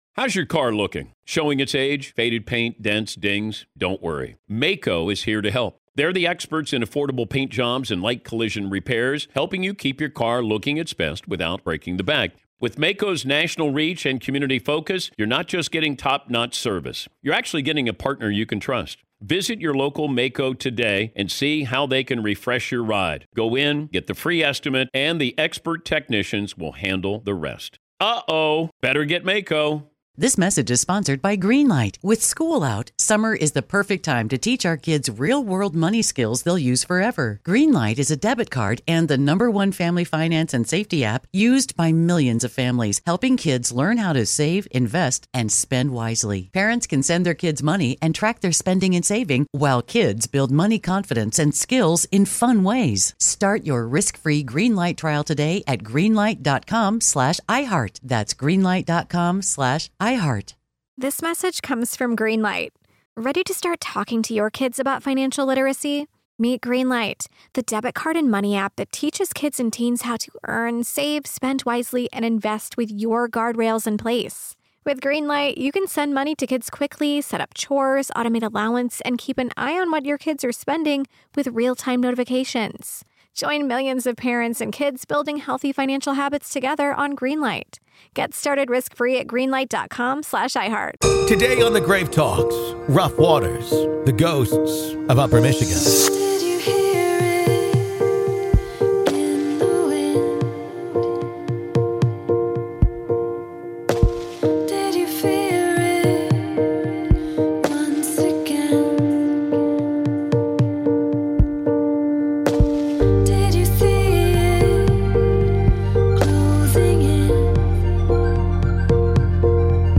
In part two of our interview